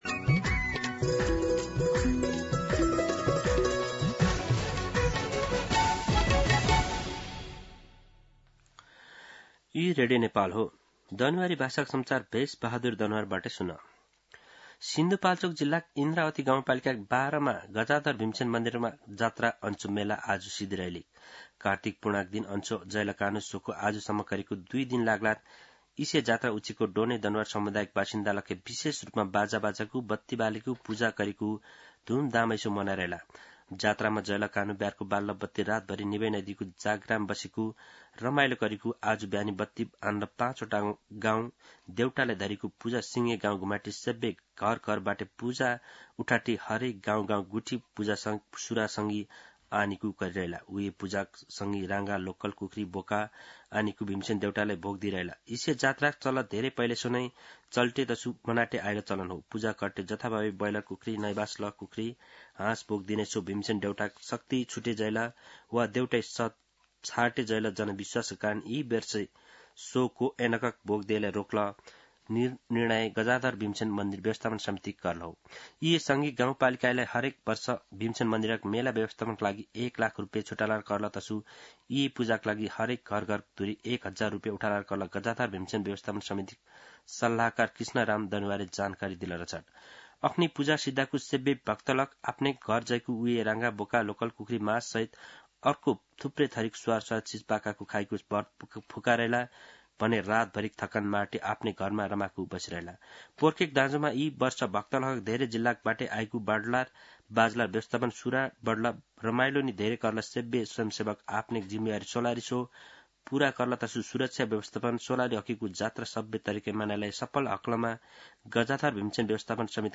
दनुवार भाषामा समाचार : १९ कार्तिक , २०८२
Danuwar-News-1.mp3